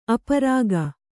♪ aparāga